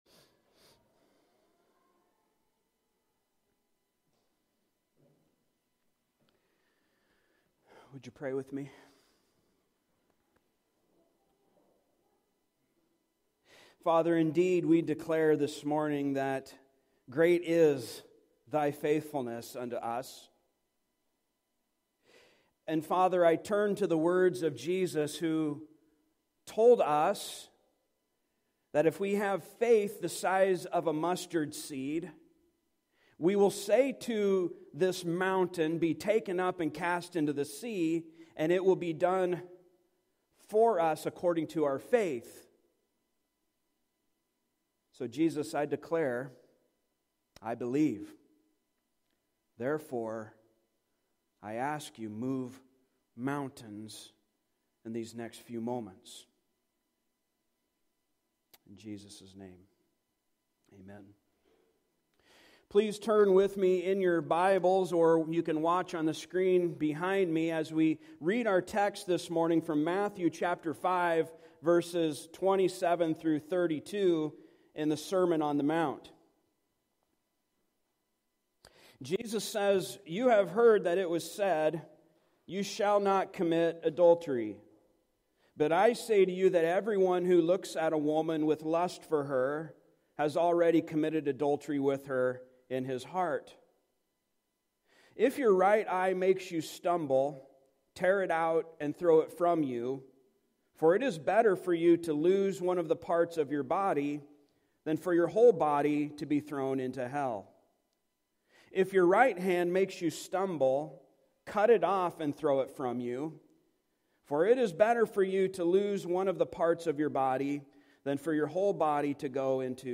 Service Type: Sunday Morning Topics: Adultery , Divorce , Law